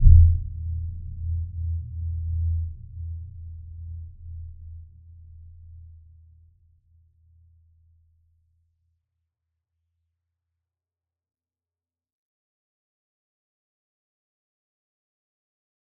Dark-Soft-Impact-E2-f.wav